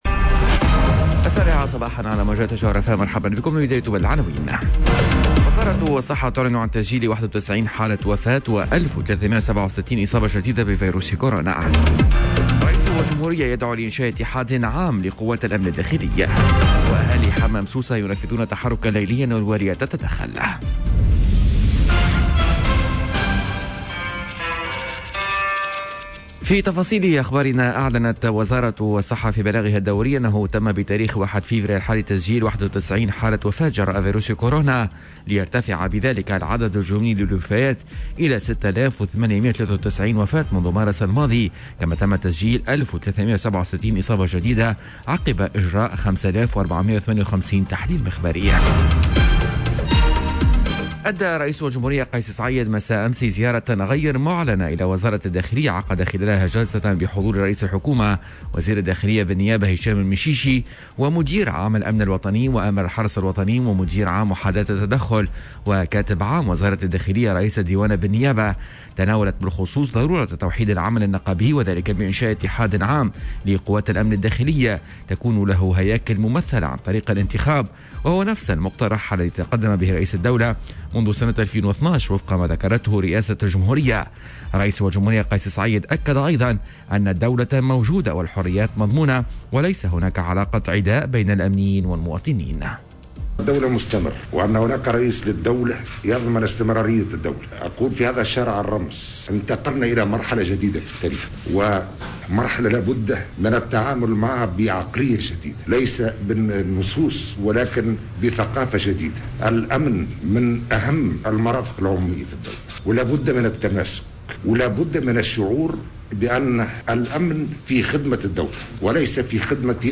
نشرة أخبار السابعة صباحا ليوم الإربعاء 03 فيفري 2021